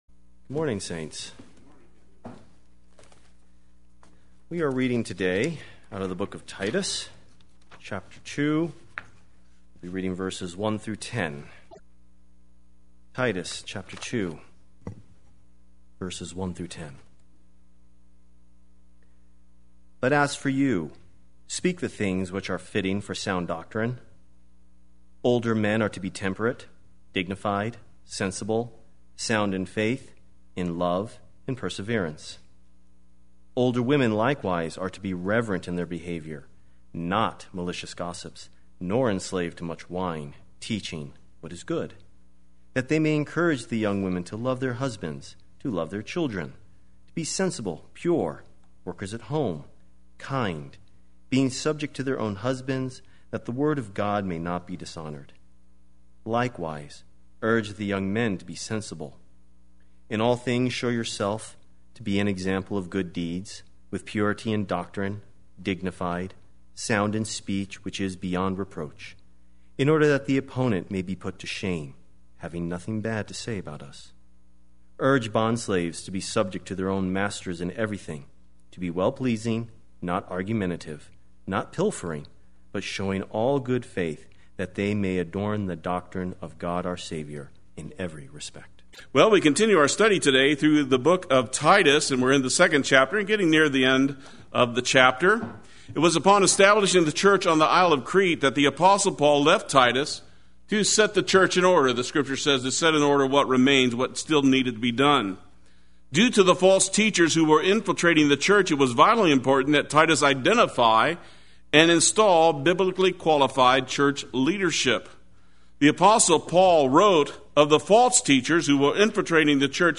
Play Sermon Get HCF Teaching Automatically.
Part 5 Sunday Worship